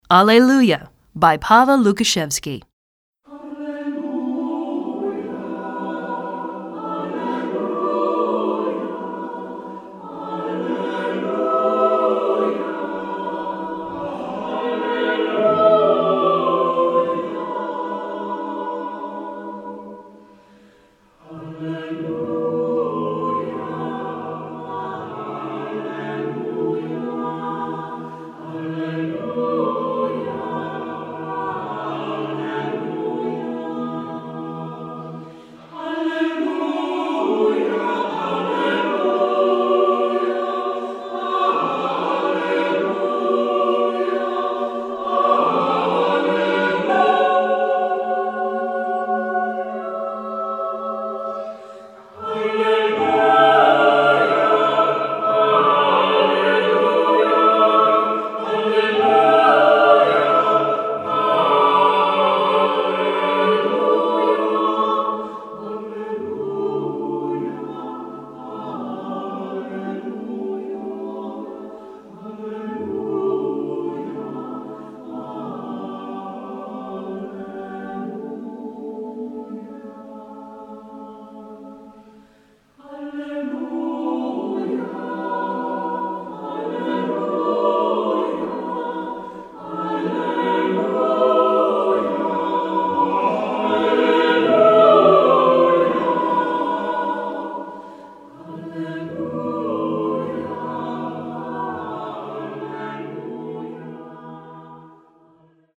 Choeur Mixte SATB a Cappella